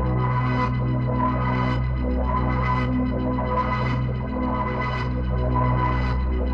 Index of /musicradar/dystopian-drone-samples/Tempo Loops/110bpm
DD_TempoDroneE_110-B.wav